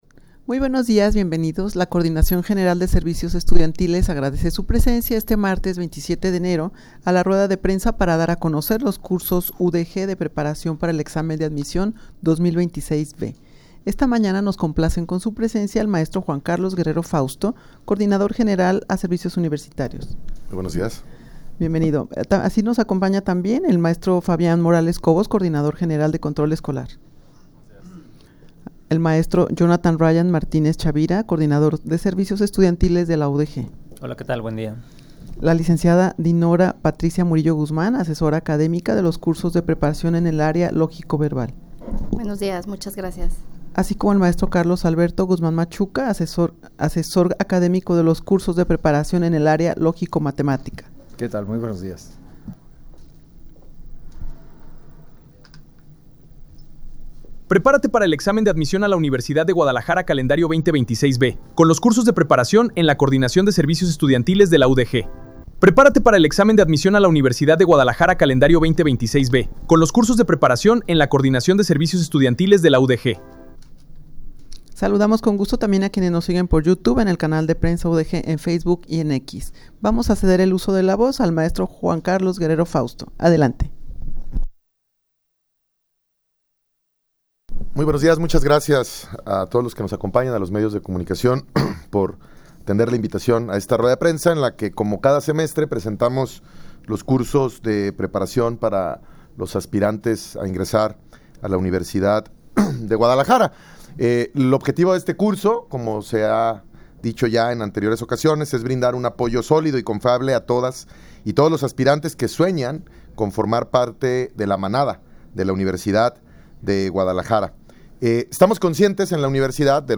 rueda-de-prensa-para-dar-a-conocer-los-cursos-udeg-de-preparacion-para-el-examen-de-admision-2026-b.mp3